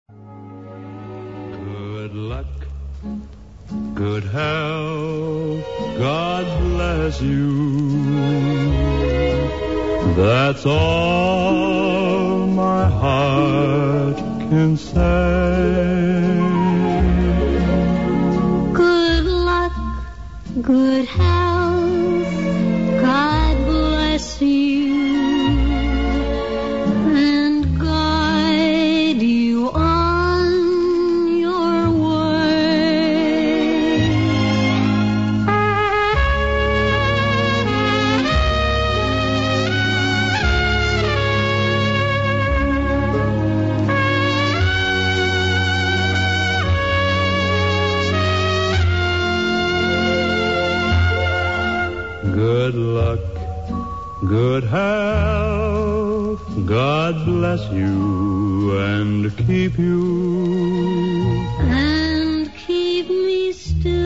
This is a very corny song, BTW...